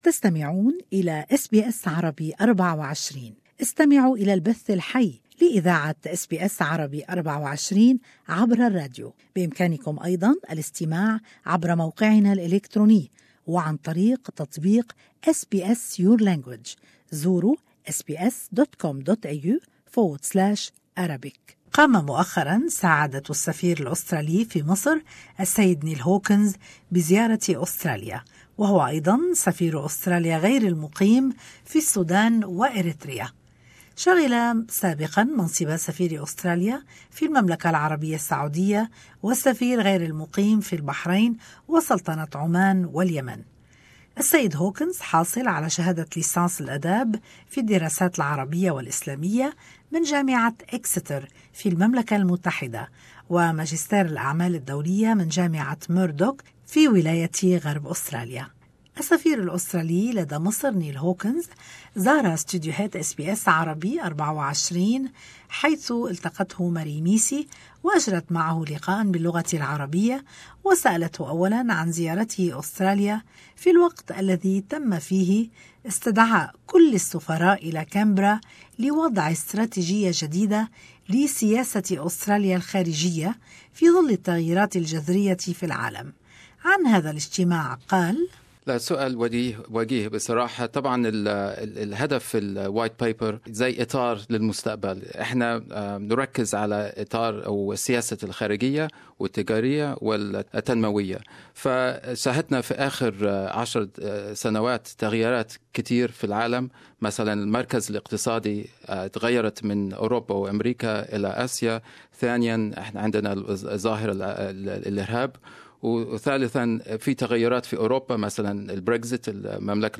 Interview with his Excellency Neil Hawkins Australian Ambassador To Egypt